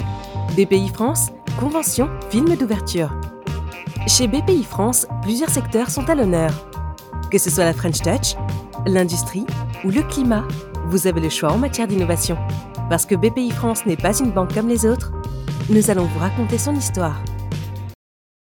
Voix off
Timbre vocal polyvalent selon les besoins, ma voix transmet énergie et enthousiasme. Mon style allie sourire et bienveillance, offrant à vos productions une touche de de chaleur.
- Soprano